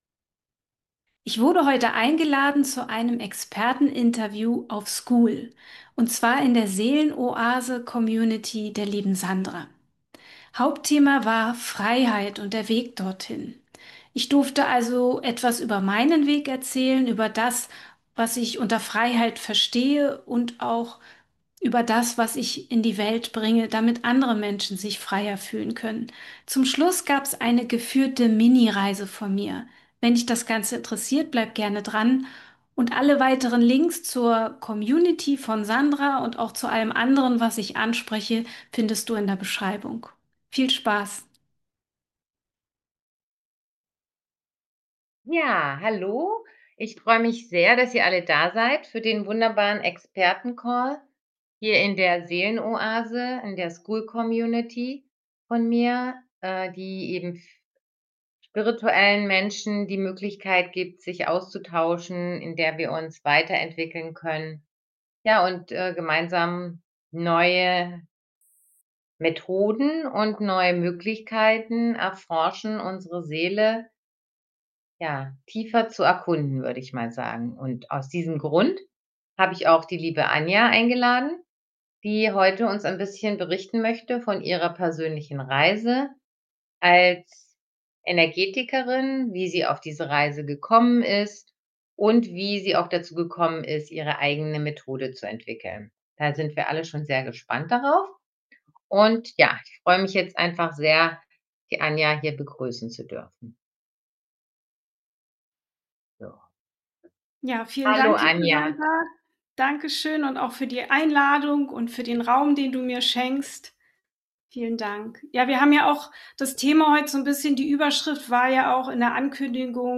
Wege in die Freiheit - Expertentalk & Meditation ~ Herzfalter - Energiearbeit für die Ohren Podcast